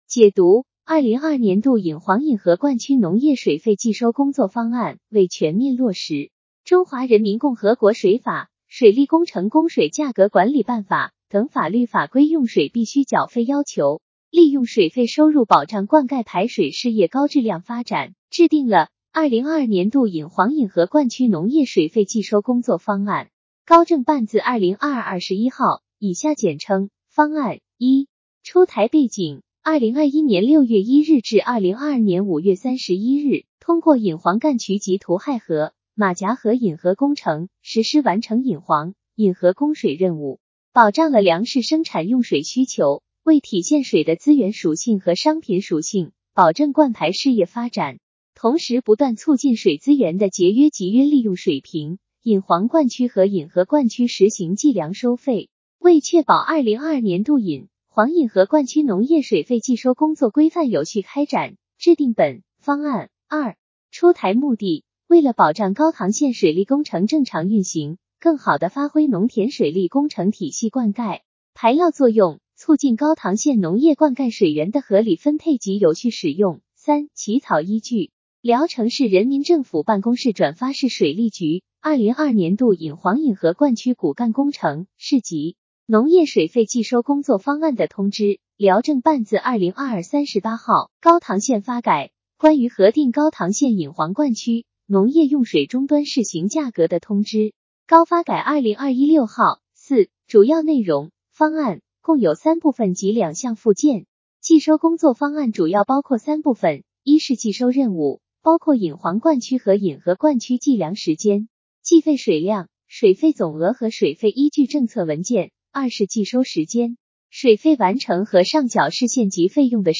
音频解读：高唐县人民政府办公室关于印发2022年度引黄引河灌区农业水费计收工作方案的通知（见附件）